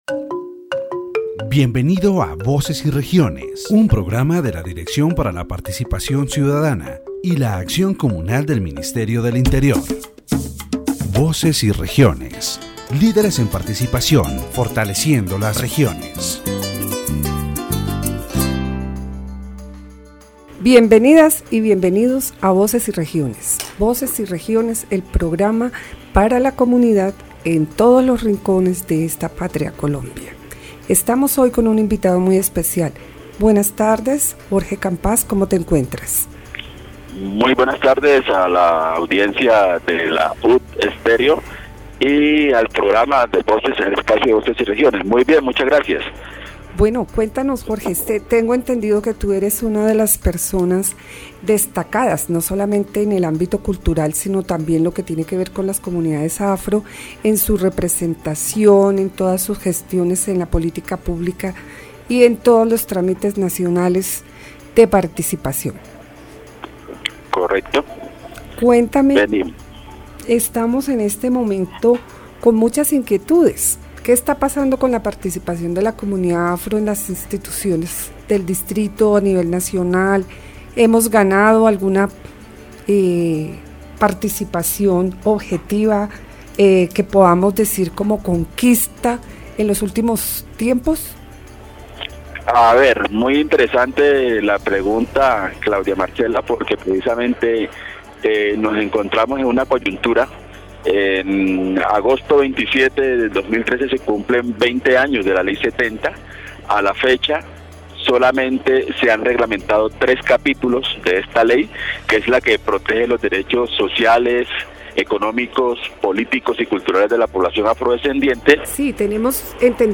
The radio program